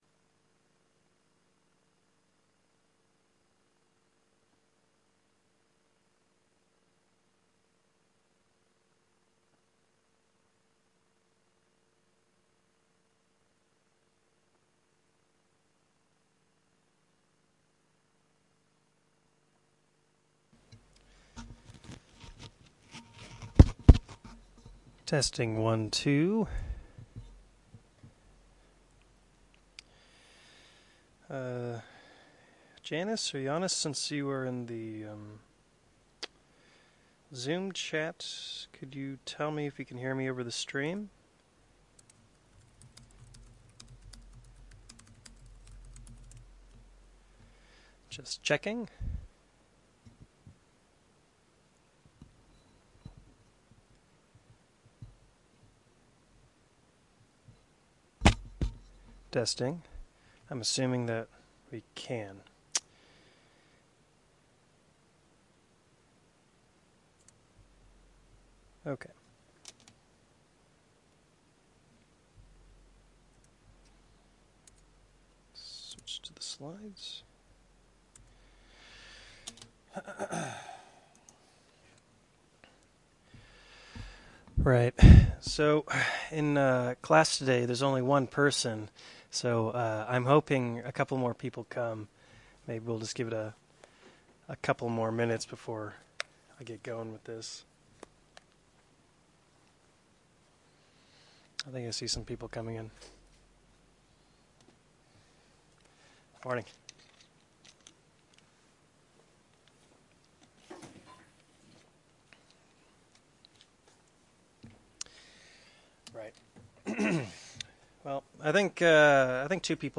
Tutorial 4